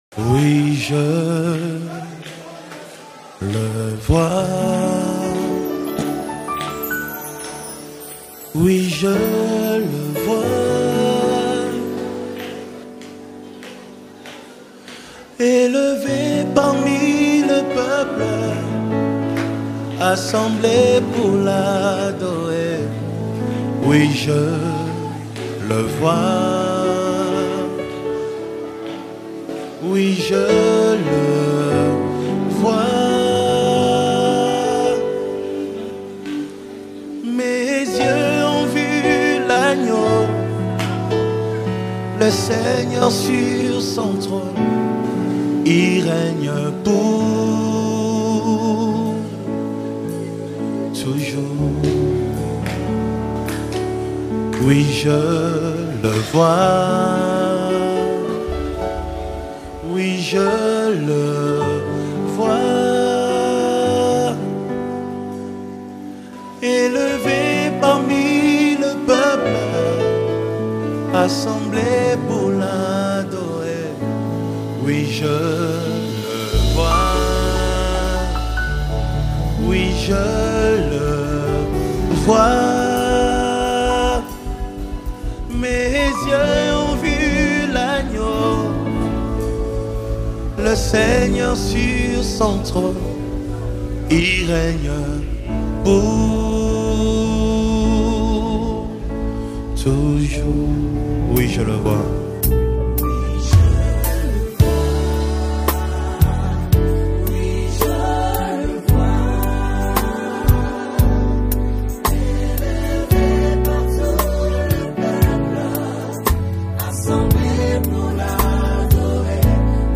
INSPIRATIONAL WORSHIP ANTHEM